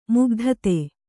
♪ mugdhate